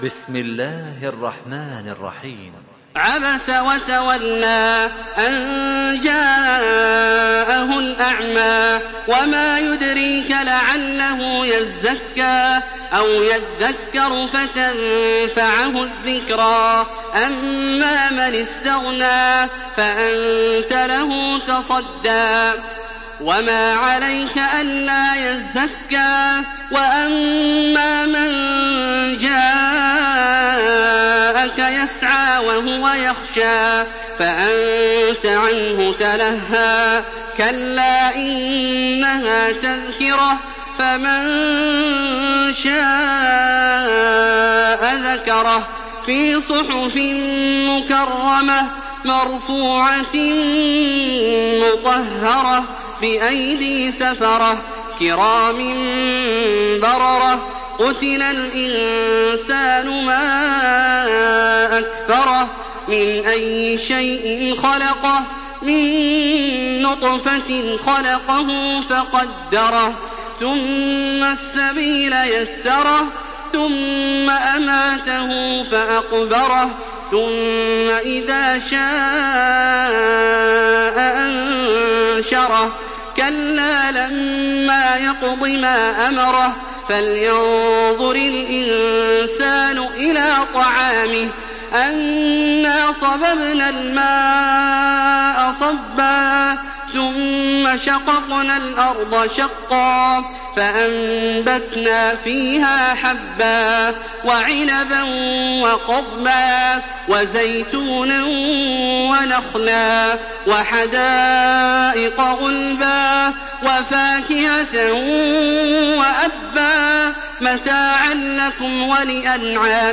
تحميل سورة عبس بصوت تراويح الحرم المكي 1427